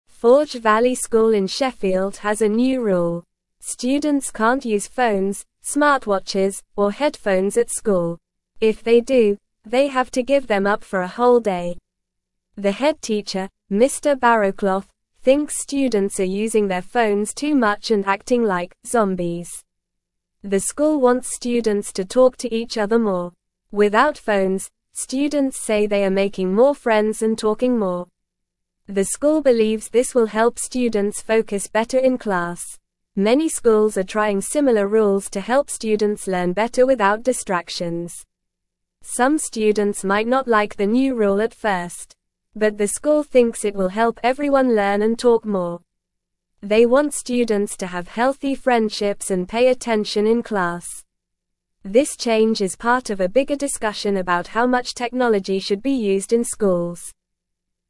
Normal
English-Newsroom-Lower-Intermediate-NORMAL-Reading-No-Phones-Allowed-at-Forge-Valley-School-in-Sheffield.mp3